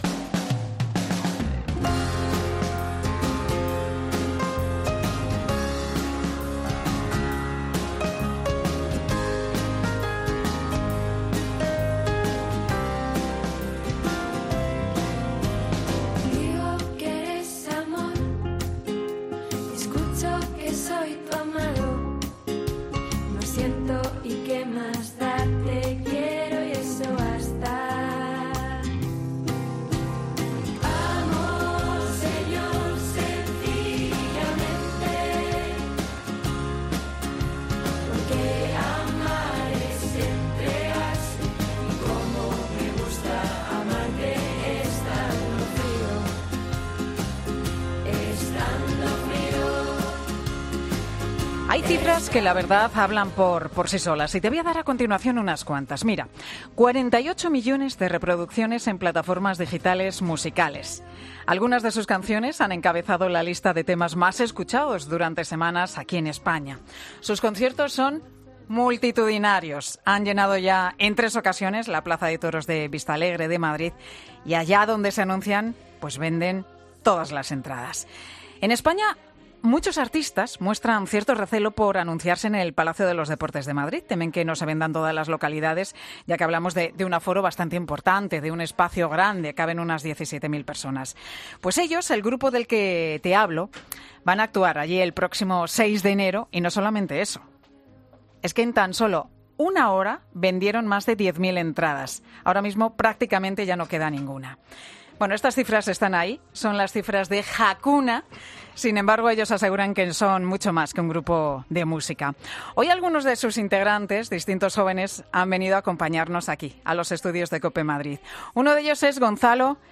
Hakuna actúa de forma improvisada en Mediodía COPE: "Jamás lo pensamos"